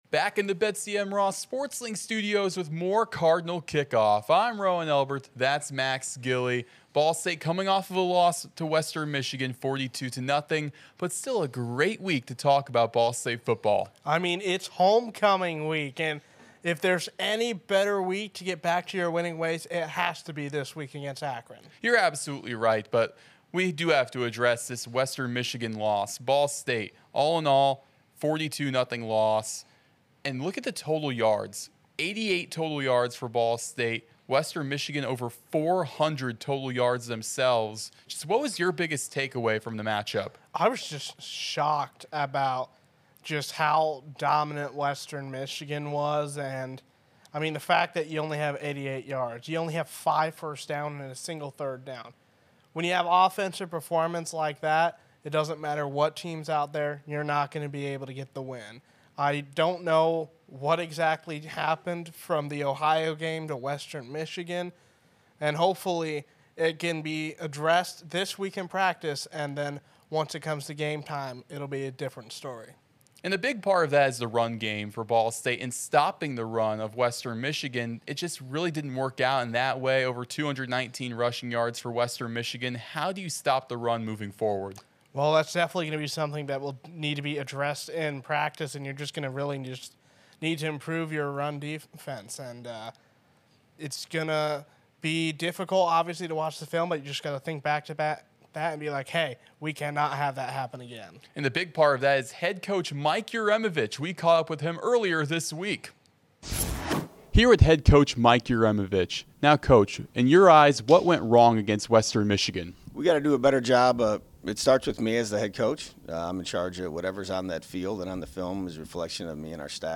Each week go inside Ball State Football with exclusive interviews, feature stories, and discussion.